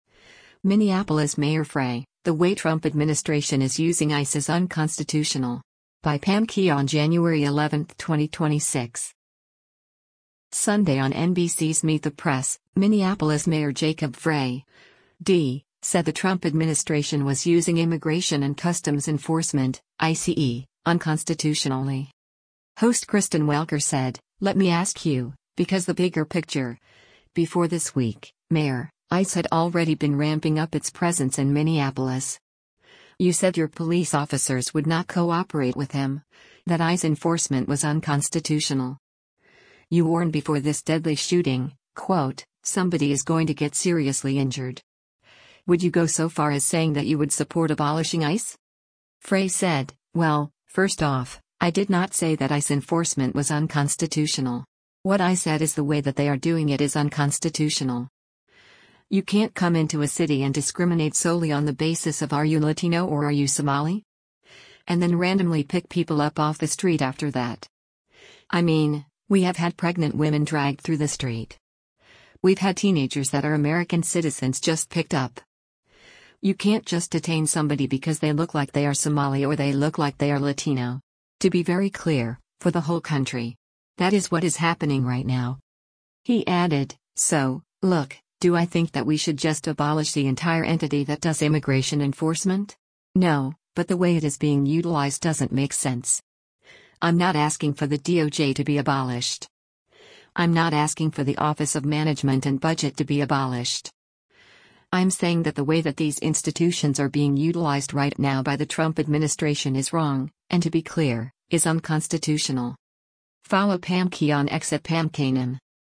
Sunday on NBC’s “Meet the Press,” Minneapolis Mayor Jacob Frey (D) said the Trump administration was using  Immigration and Customs Enforcement (ICE) unconstitutionally.